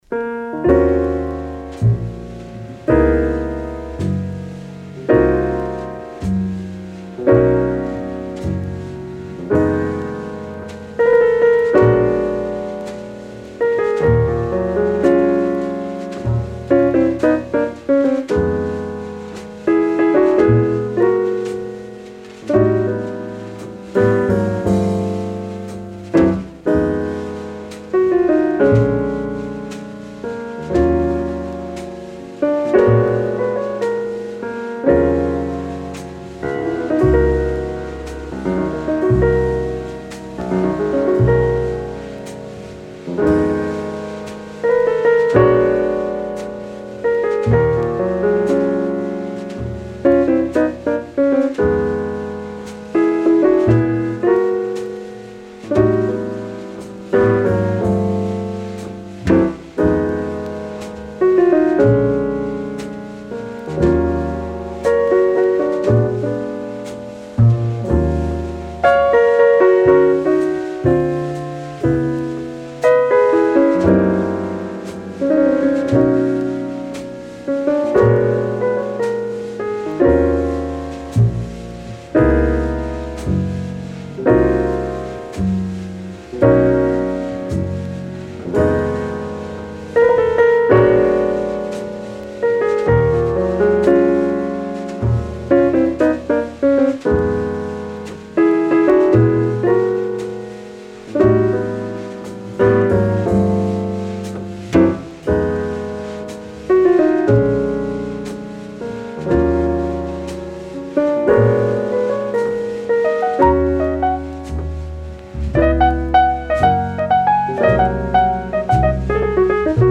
Recorded at Van Gelder Studio, Englewood Cliffs, New Jersey